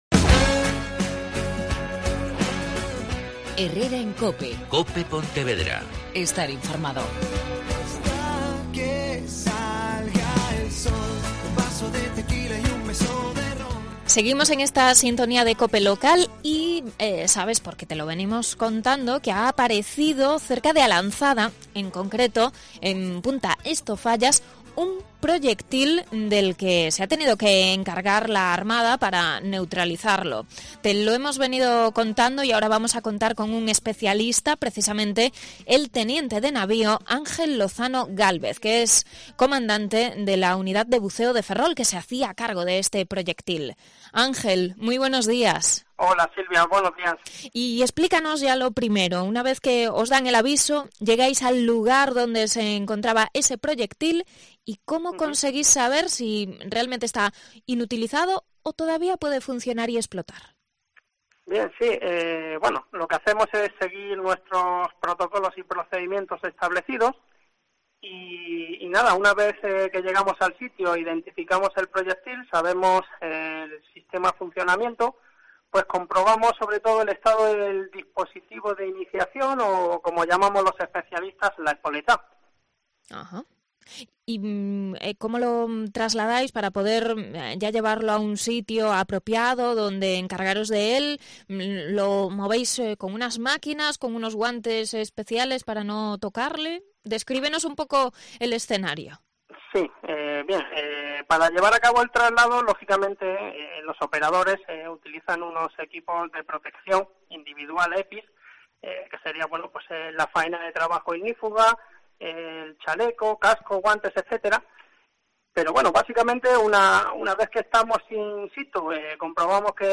Un especialista de la Armada explica en COPE las características del proyectil de A Lanzada